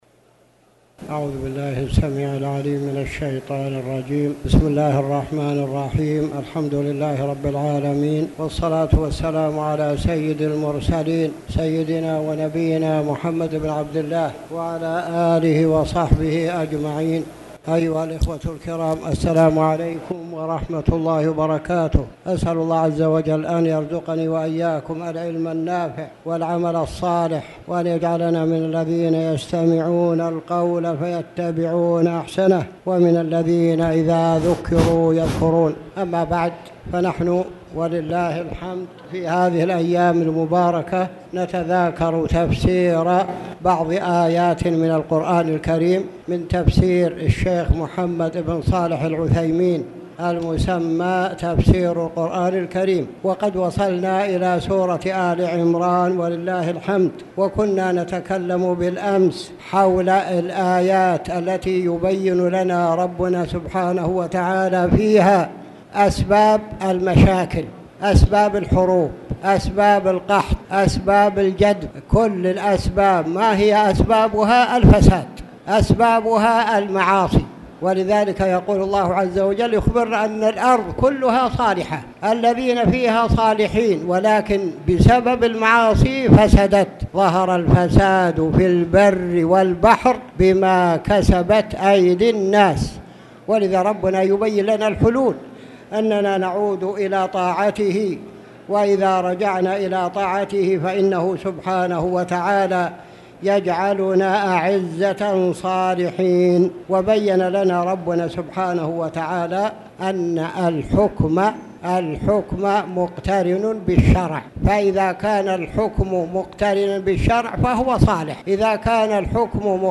تاريخ النشر ٥ رمضان ١٤٣٨ هـ المكان: المسجد الحرام الشيخ